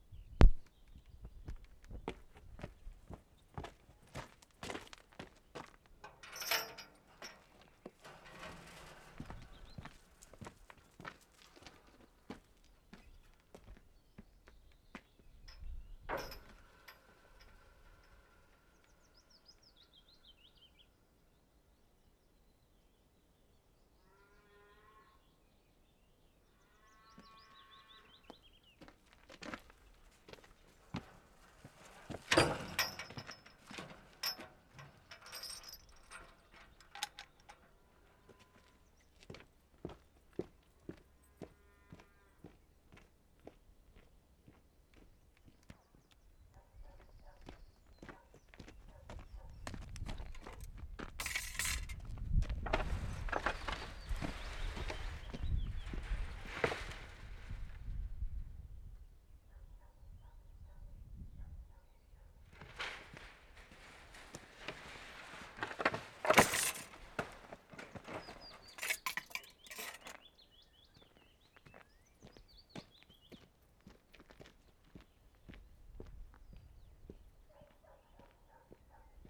Wensleydale, England May 30/75
wide wooden gate.
Often the gate itself is quite silent, only the latch is heard opening and shutting. All examples are clear - the ambient level in each case is very low.